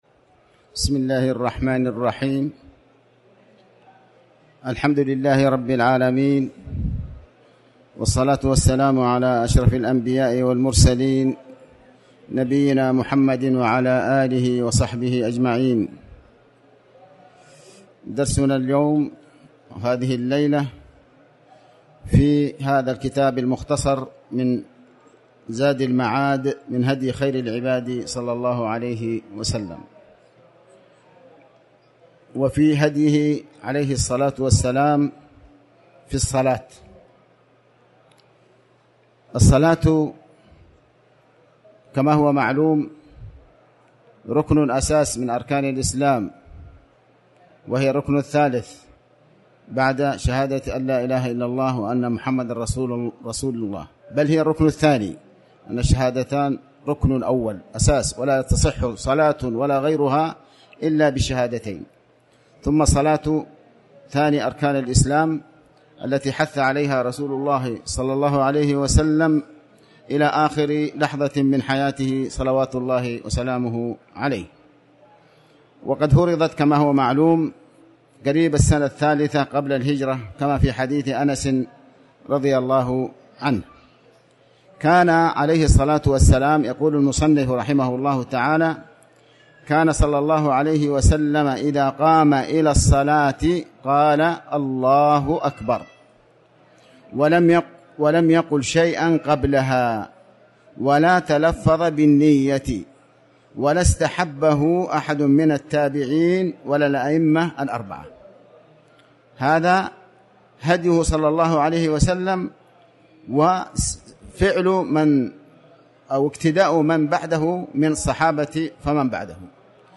تاريخ النشر ٨ صفر ١٤٤٠ هـ المكان: المسجد الحرام الشيخ: علي بن عباس الحكمي علي بن عباس الحكمي هديه صلى الله علية وسلم